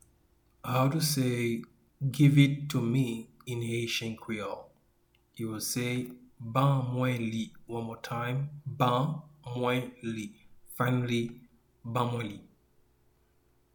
a native Haitian voice-over artist can be heard in the recording here: